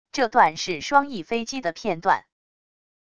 这段是双翼飞机的片段wav音频